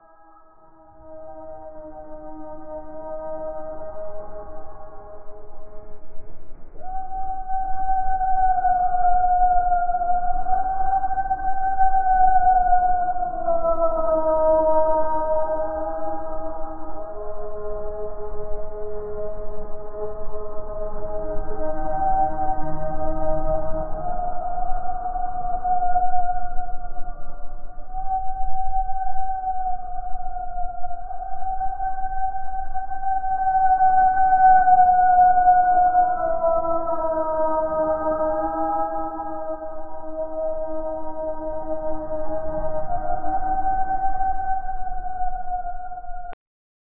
Hieroglyph-adorned walls, colorful fr 0:05 abandoned ruins of ancient civilisation filled with nature 0:47
abandoned-ruins-of-ancien-tw4f63rz.wav